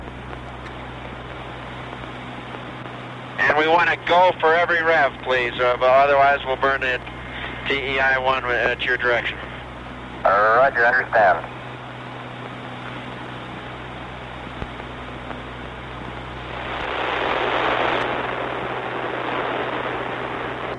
Audio recorded at Honeysuckle Creek